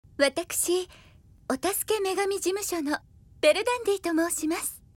Jej śliczny, delikatny głos jest jednym z najlepiej rozpoznawanych wśród żeńskiej cześci seiyuu.